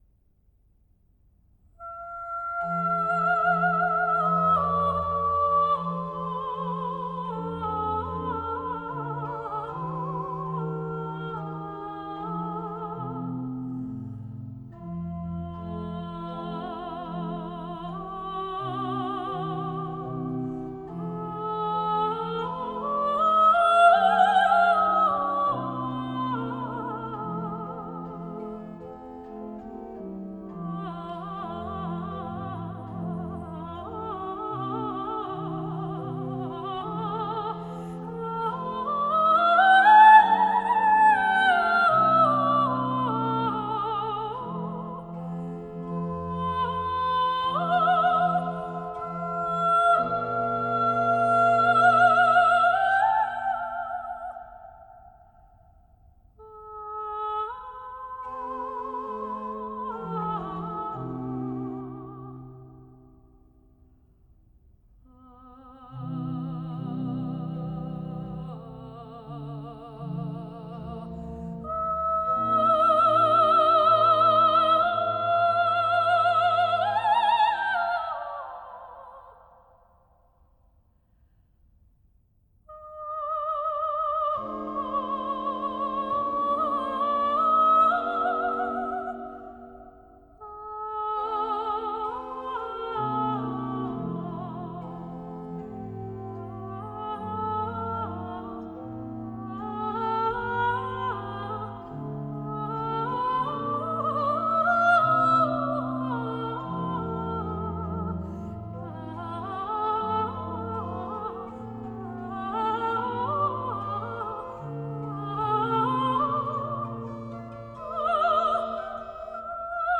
没有歌词，一个字都没有。